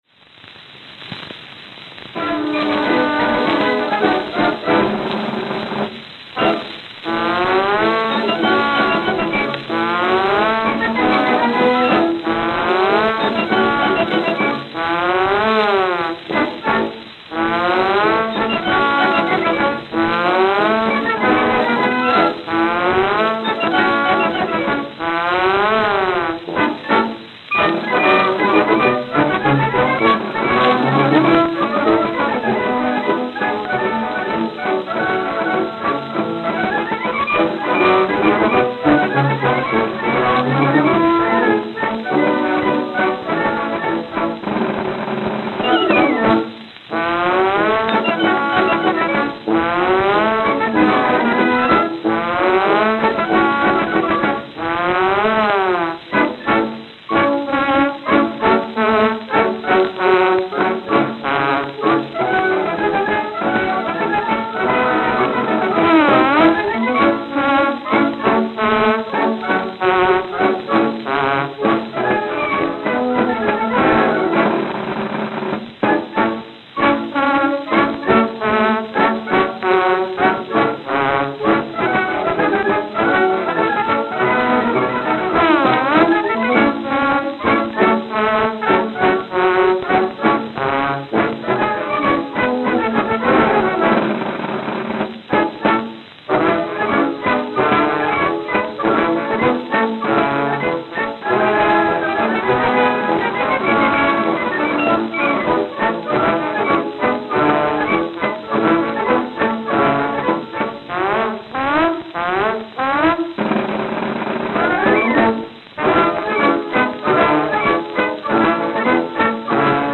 Note: Worn.